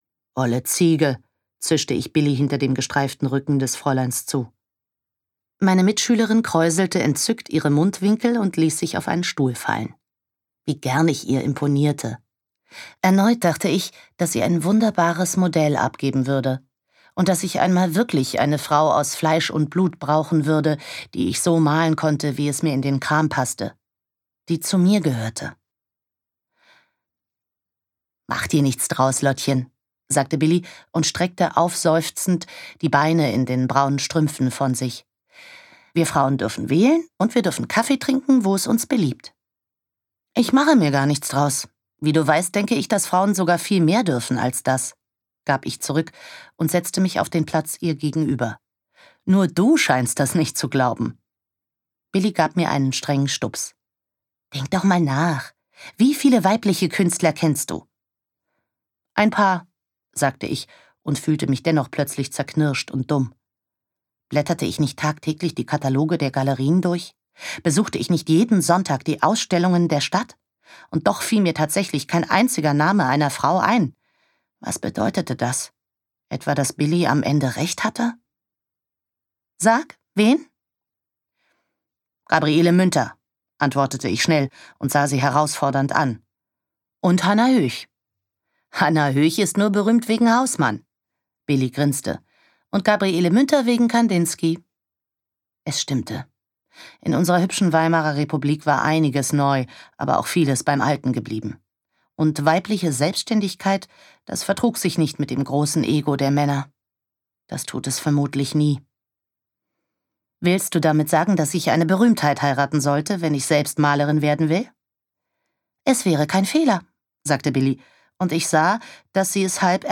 1. Autorisierte Lesefassung